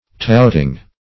Touting - definition of Touting - synonyms, pronunciation, spelling from Free Dictionary
touting.mp3